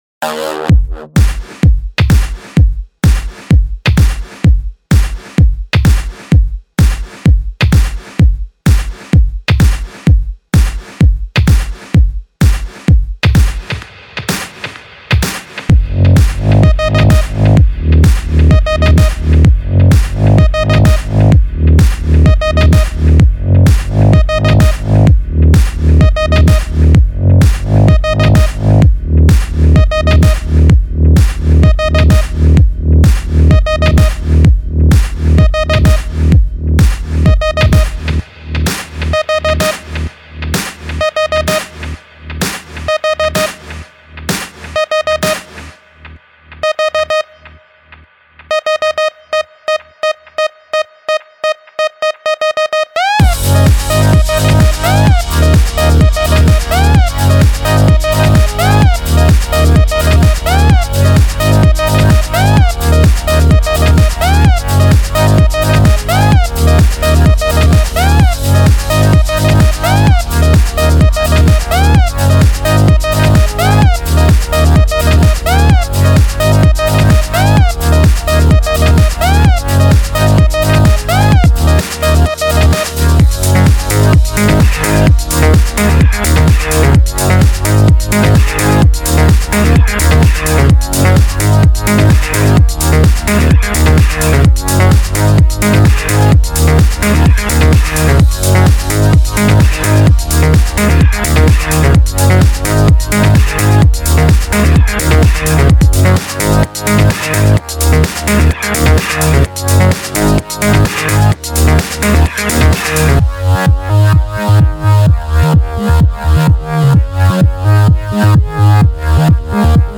З.З.Ы. Каг называеццо трег в стиле Electro, который на форумчиге играед?